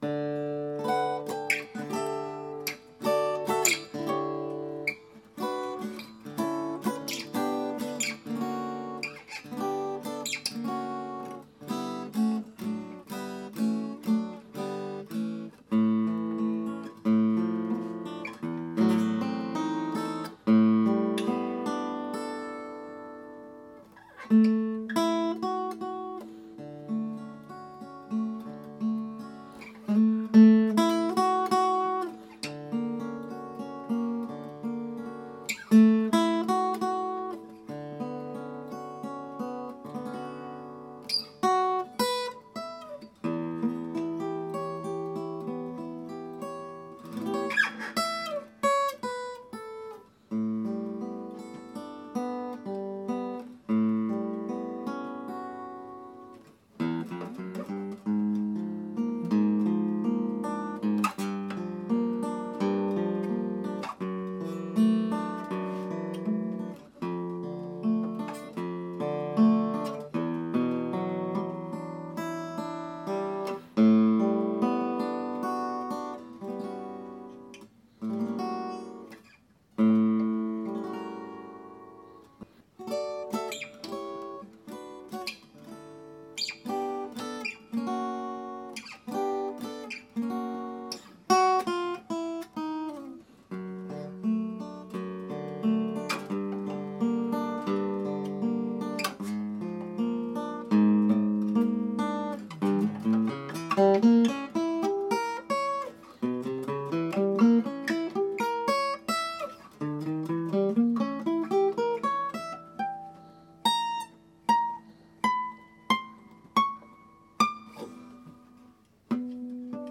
Although the top is laminated, with bronze strings it produces a pleasing sound and gives me immense pleasure to play.
Recorded a short take with the Huttl.
Thank you for sharing this, your Hüttl sounds as good as it looks like!